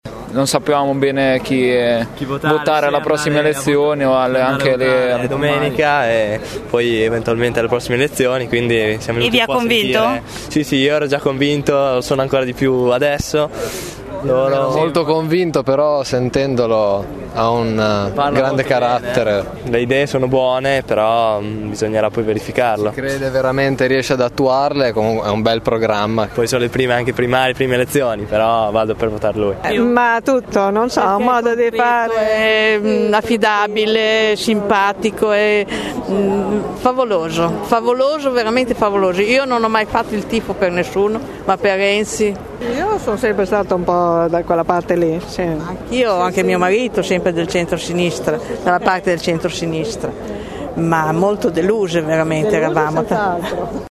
Nel pubblico alcuni giovanissimi ma anche tanti e tante non più di “primo pelo“, che invocano il suo nome, cercano l’autografo e intervengono a voce alta durante il monologo per assicurare al rottamatore che “il tam tam” che chiede per poter vincere” è già partito”. Alcune voci